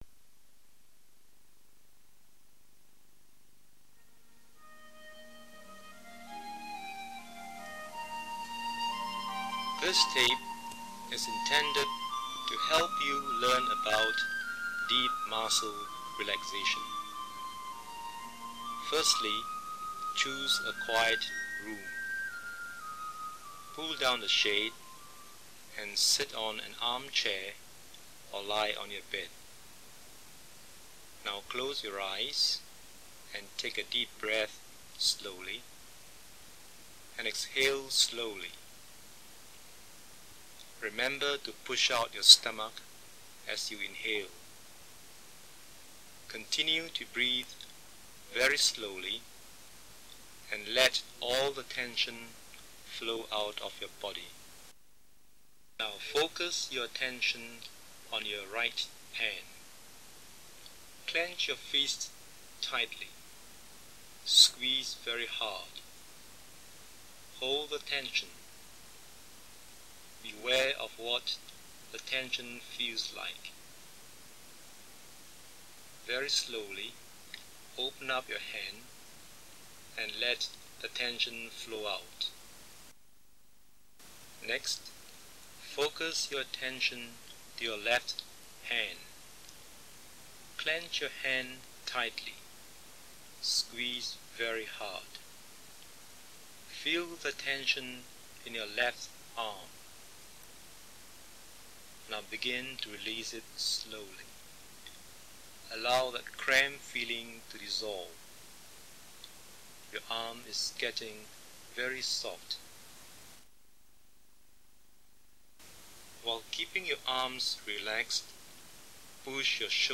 Muscle-relaxation-therapy.mp3